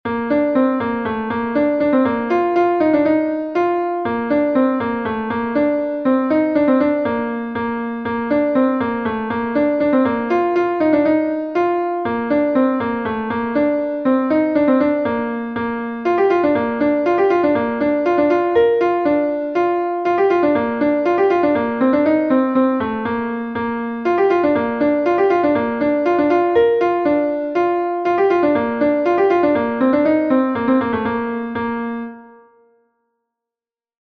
Gavotenn Er Hroesti I est un Gavotte de Bretagne